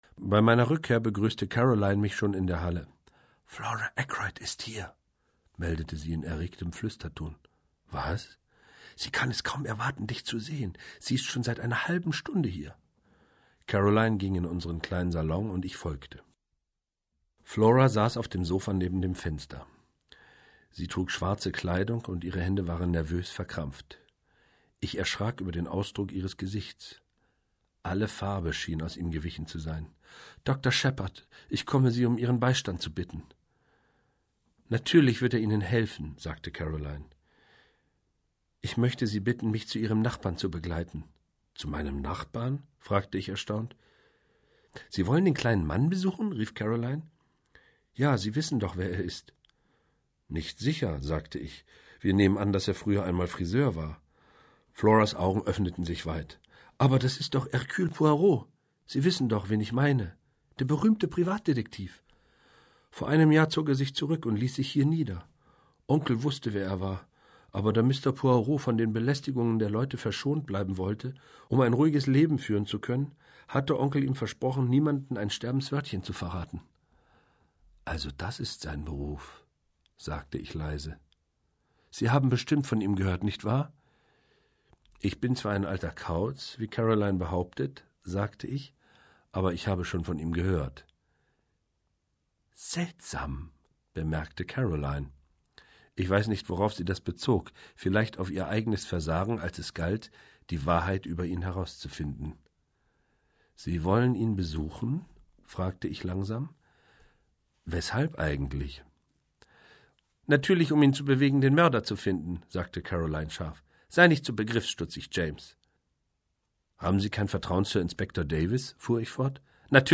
Charly Hübner (Sprecher)
Ungekürzte Lesung